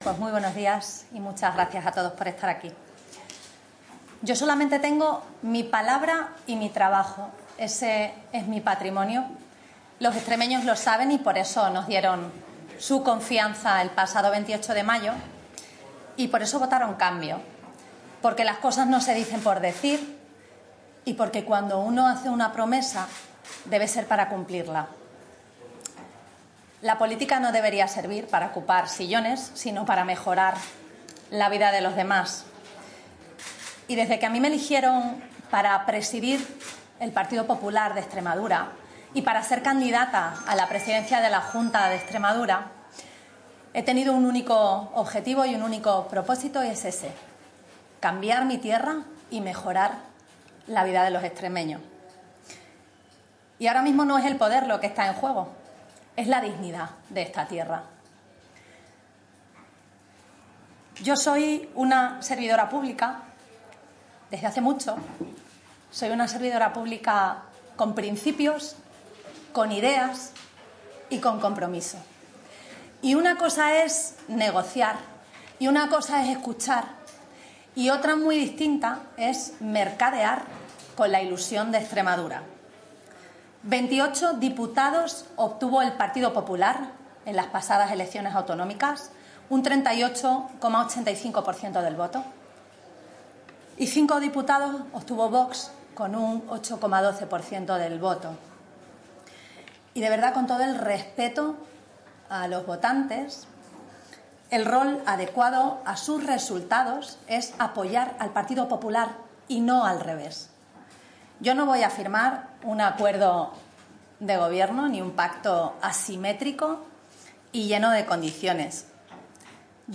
Sus gestos en el hemiciclo ya denotaban el desastre, su salida a toda prisa también y su prevista declaración ha sido toda una suerte de razones, afirmaciones, excusas y evidente cabreo ante la situación que aunque posible no por menos ha sido molesta, por no decir jodida.